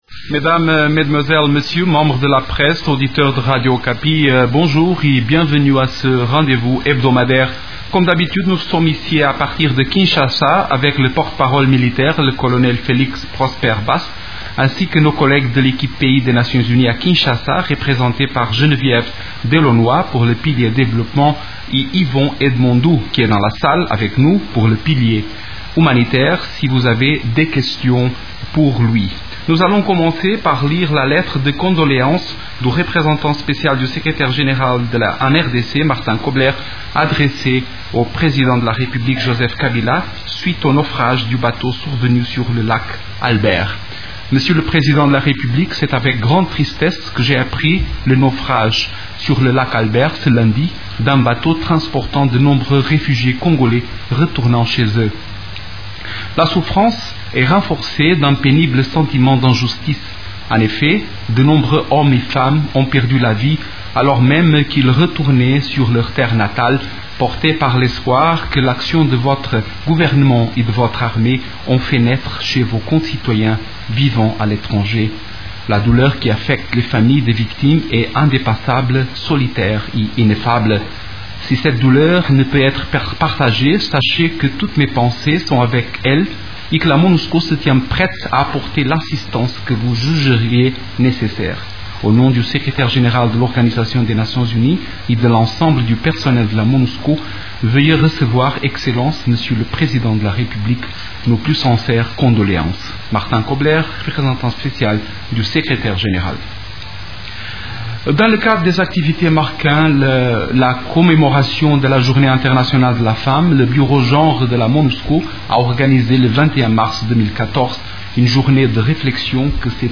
La conférence hebdomadaire des Nations unies du mercredi 26 mars à Kinshasa a abordé les sujets suivants: